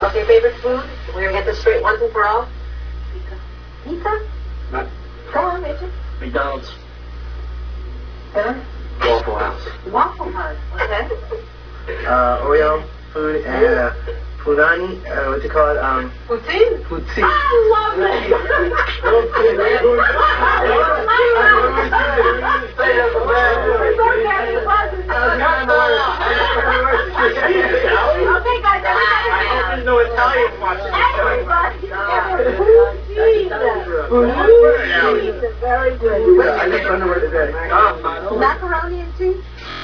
You can learn more about them through these audio interviews of themselves!
BSB Interview #10: Their favourite food (VERY CUTE!)